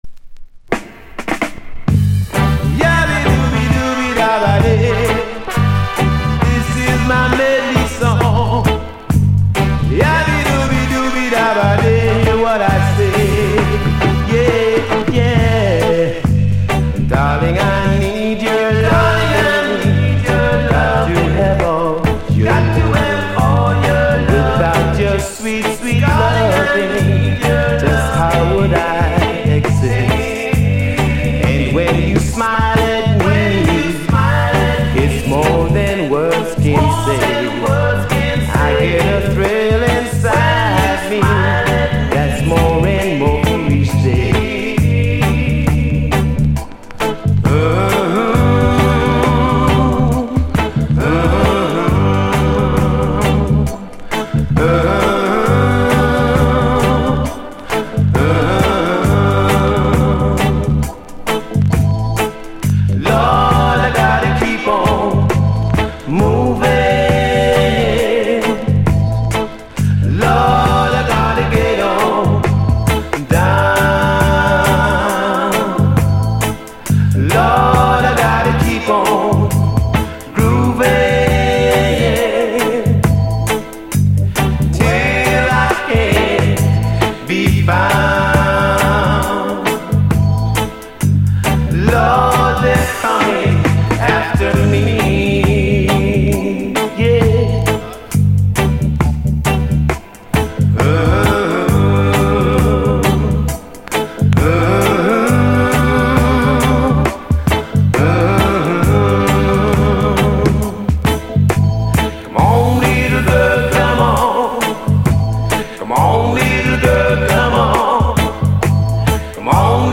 Genre Reggae70sLate / Male Vocal
*ラヴァーズ・スタイルのレゲエ・メドレー / ルーツレゲエ後半DJ接続のヤードスタイリー。